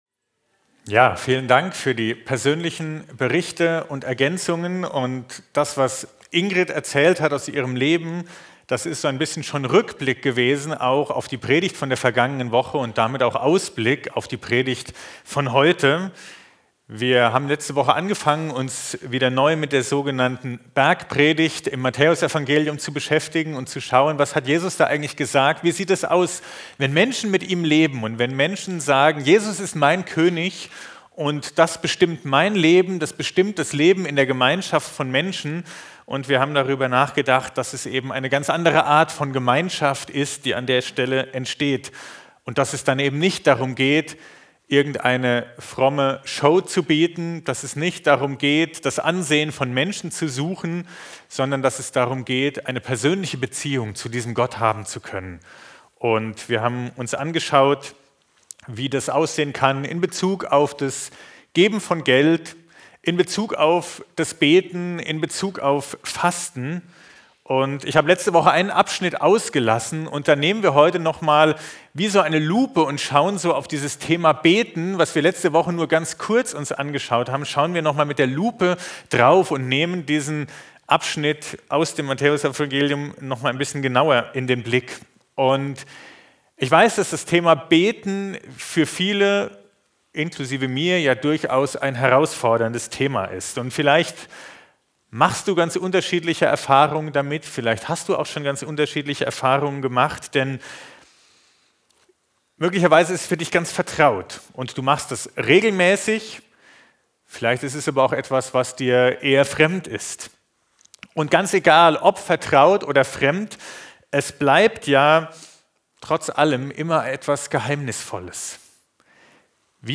Predigten Sammlung